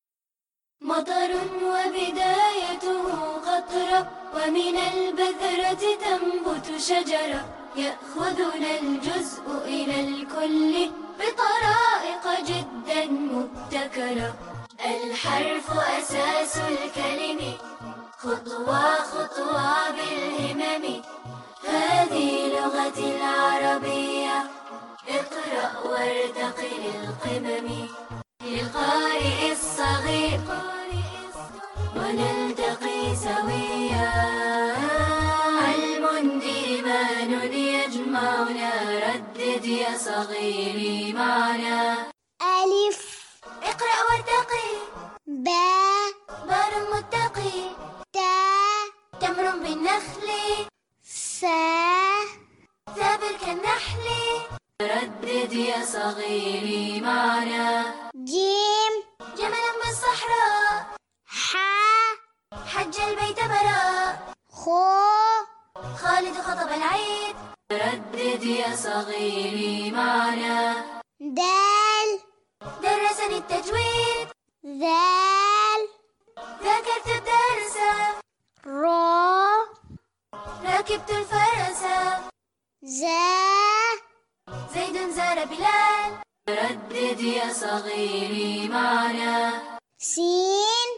نشيد اقرأ وارتق للحروف
نشيد-الحروف-دون-الموسيقي-للموقع.mp3